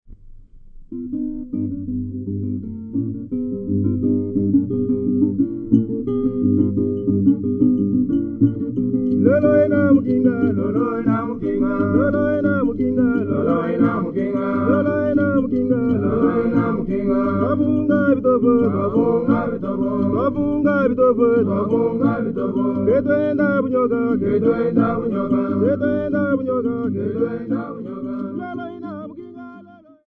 9 Luba men
Folk music--Africa
Field recordings
Topical song with 2 guitars, bottle and rattle accompaniment.
96000Hz 24Bit Stereo